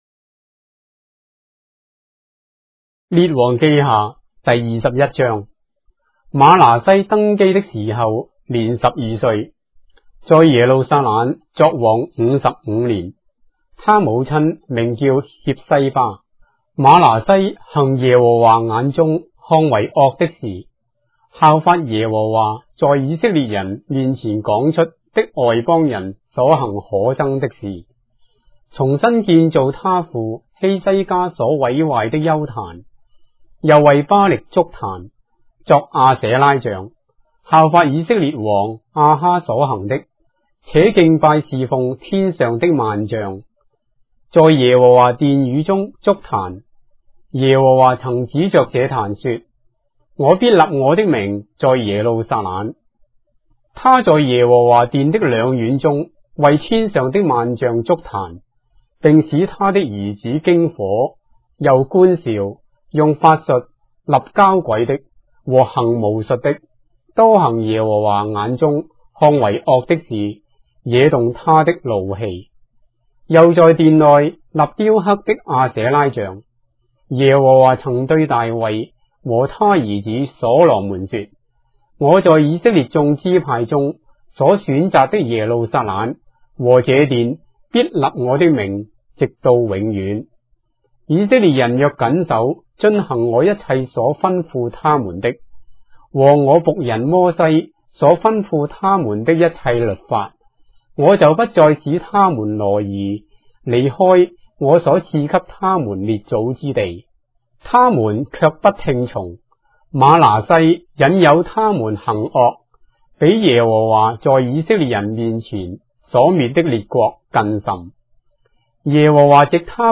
章的聖經在中國的語言，音頻旁白- 2 Kings, chapter 21 of the Holy Bible in Traditional Chinese